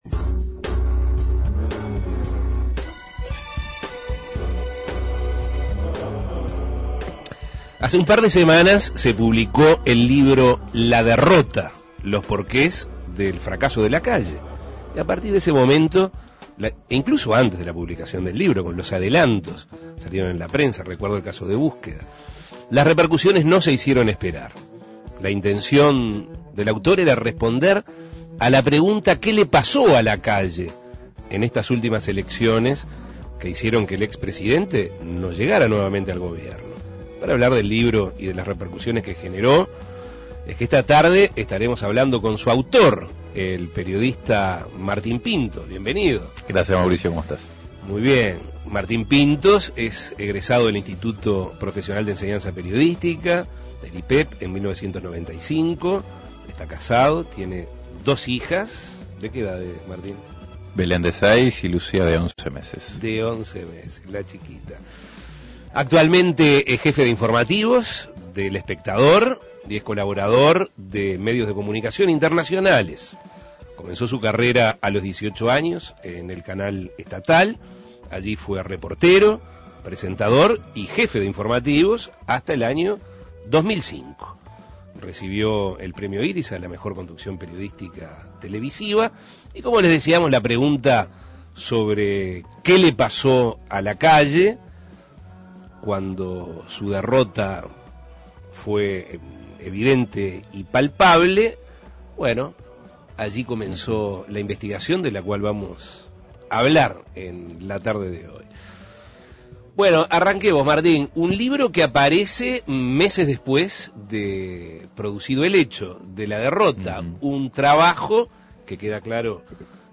También, habló sobre los motivos que lo llevaron a escribirlo, cómo tomó las repercusiones y qué errores hicieron que el ex presidente perdiera la elección ante José Mujica. Escuche la entrevista.